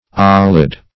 Search Result for " olid" : The Collaborative International Dictionary of English v.0.48: Olid \Ol"id\, Olidous \Ol"i*dous\, a. [L. olidus, fr. olere to smell.]